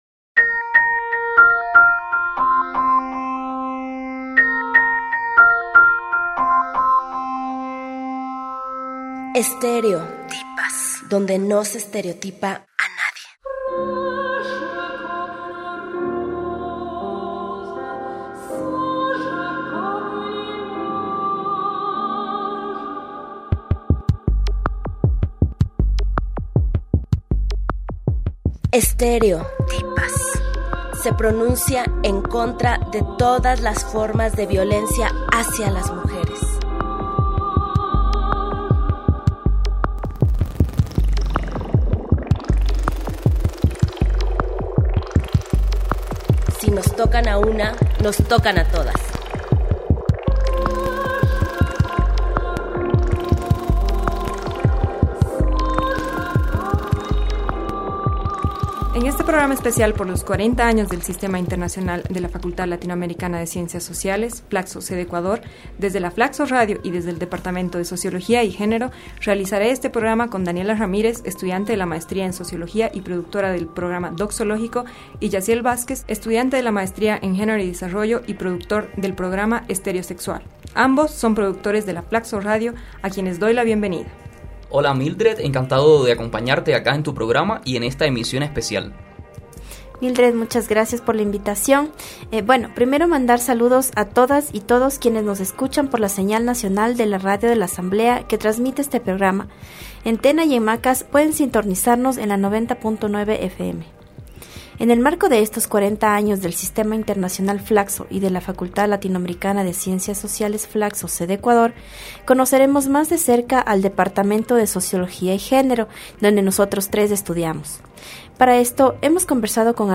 En este programa especial por los 40 años del Sistema Internacional FLACSO y de la Facultad Latinoamericana de Ciencias Sociales sede Ecuador desde FLACSO Radio y desde el departamento de Sociología y Género, realizamos un programa recorriendo los pasillos del departamento.